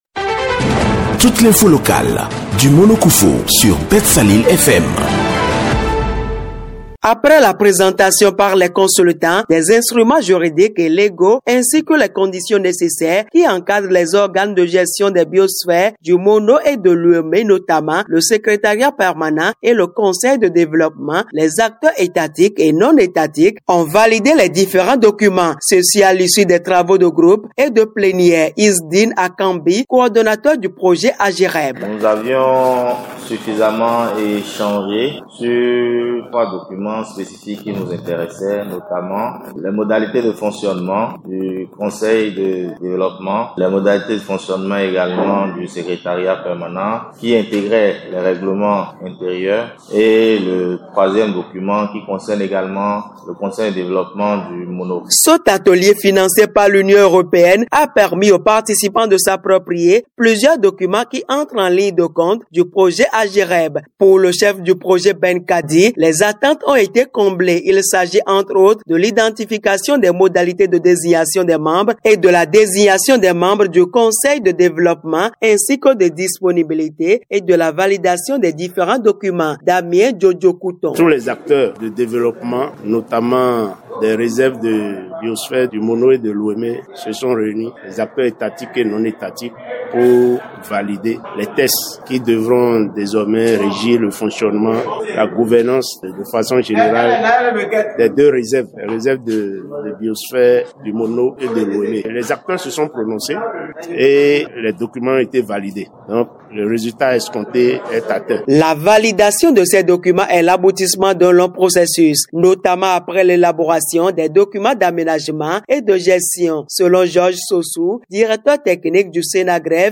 Les textes réglementaires de base des organes de gestion des réserves de biosphère du Mono et de l’Ouémé ont été adoptés. L’atelier de validation des instruments juridiques et légaux ainsi que les conditions nécessaires pour encadrer le fonctionnement et les comportements attendus au sein de ces organes de gestion s’est tenu à Grand-Popo dans le département du Mono.